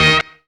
BUBBLE UP.wav